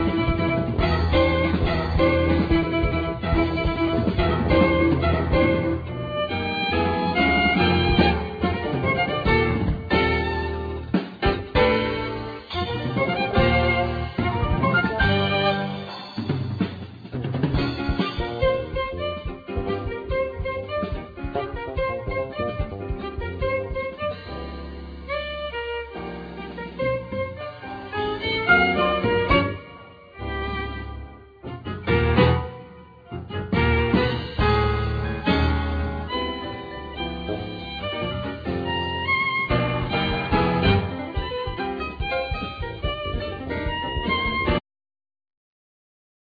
Violin
Piano
Bass
Drums